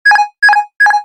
autopilot.wav